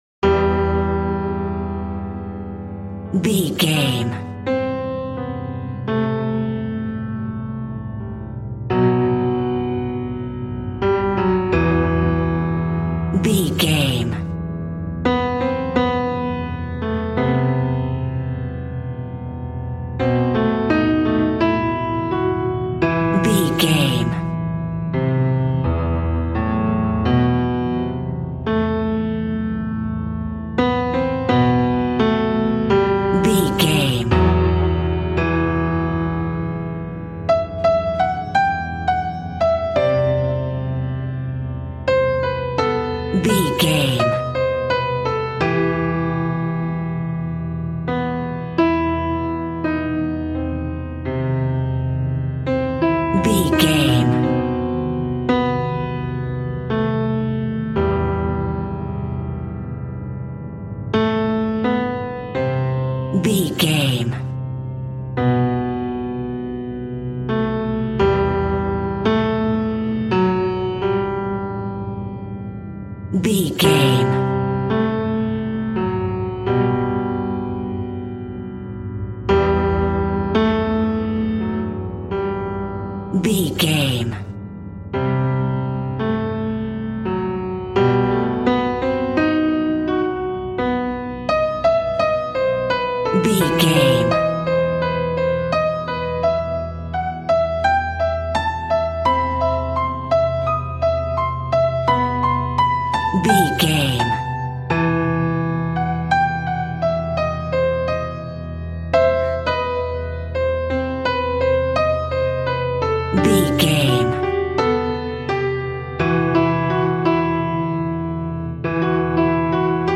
Aeolian/Minor
ominous
dark
suspense
eerie
creepy
Acoustic Piano